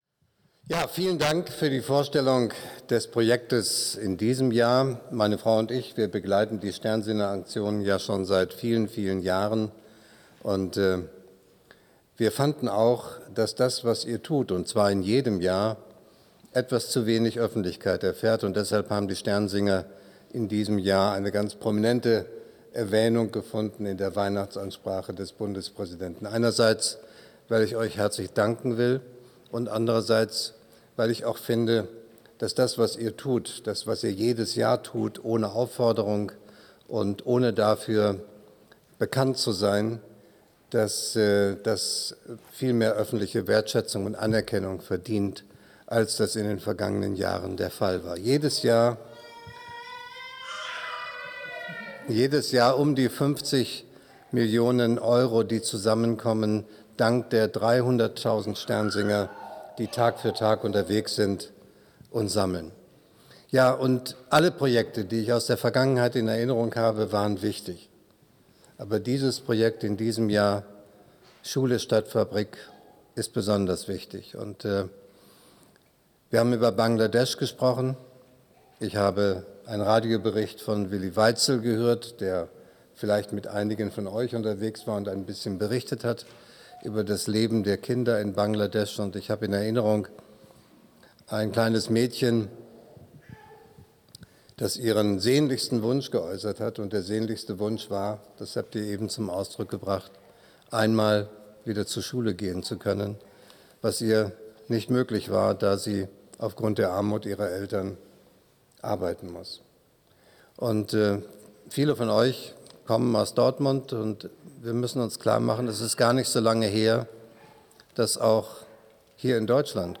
Rede_Bundespraesident.mp3